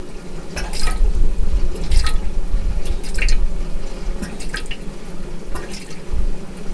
ガス管の折れや損傷によって冷媒液体が漏れた状態で，ガスで作動させた時の冷媒液体タンクから発生する音です。（実際の音）
この音は腐食による液漏れの冷蔵庫をガスで作動させた場合に発生した音を直接デジタル録音したものです